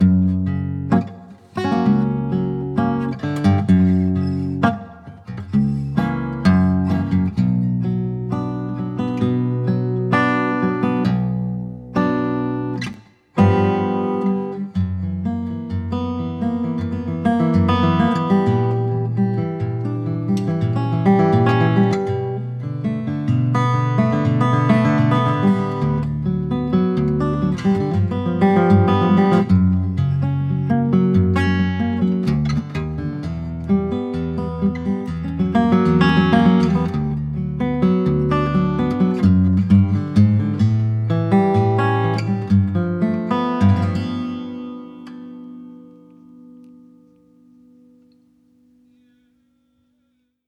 Viola De Fado 7 cordes Oscar Cardoso
Son très médium, et attaque franche mais confort de jeu incomparable. A noter , toutes les cordes sont en métal et le Si aigu est un 0.17 filé et le Mi aigu un 0,13.
Extrait d’un fado menor do porto